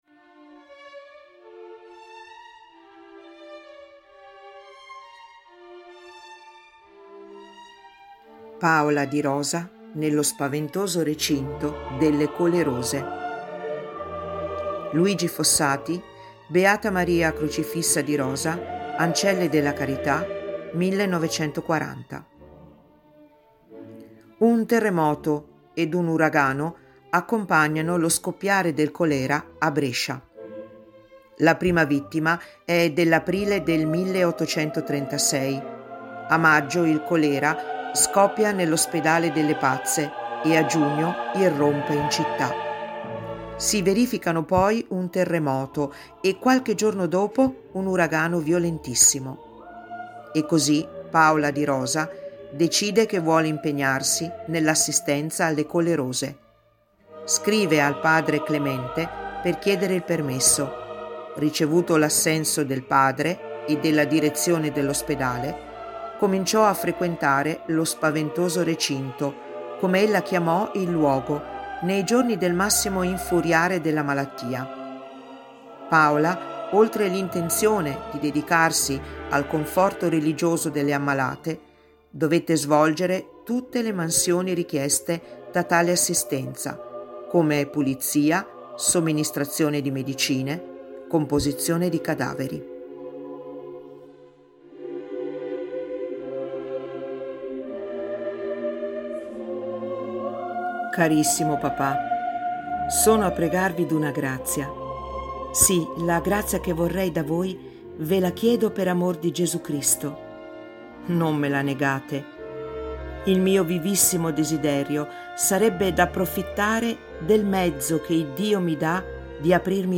Musica:
Mozart Sinfonia da Requiem- Lacrimosa